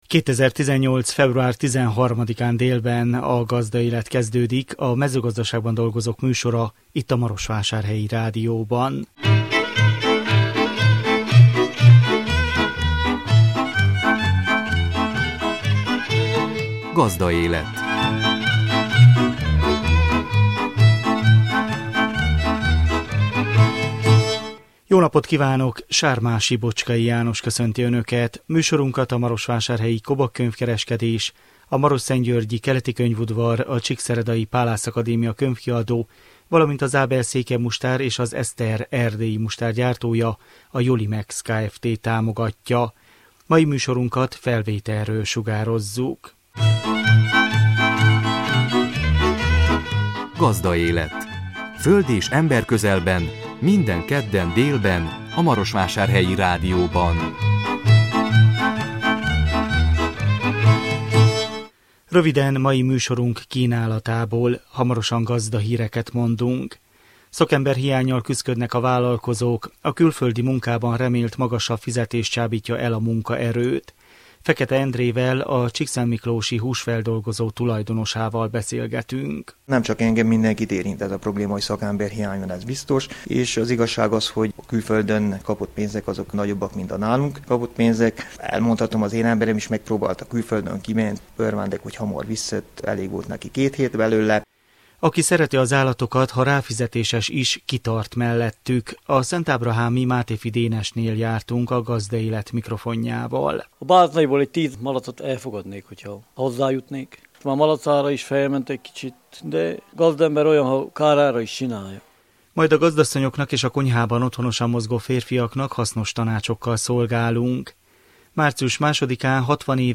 Emlékperceinkben régi hangfelvételekkel idézzük a múltat.